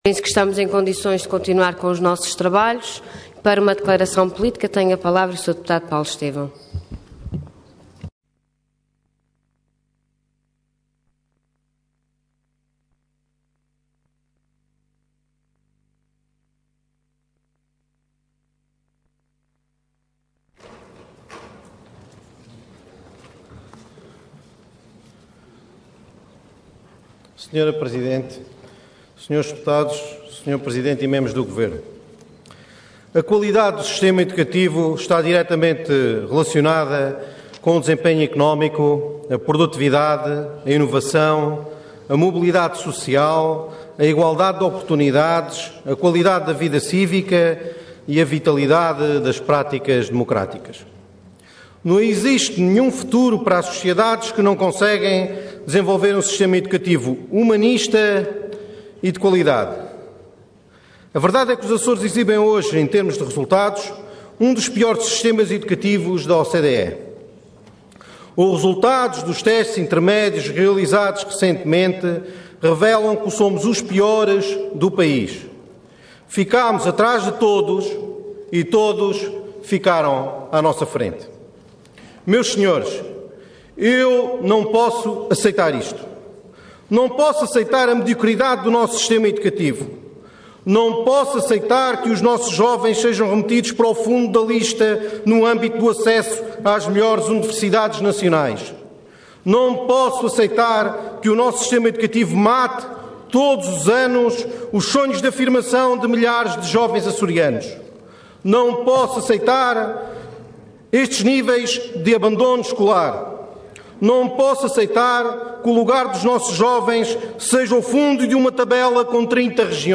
Detalhe de vídeo 16 de abril de 2013 Download áudio Download vídeo Diário da Sessão Processo X Legislatura O Sistema Educativo Regional. Intervenção Declaração Política Orador Paulo Estêvão Cargo Deputado Entidade PPM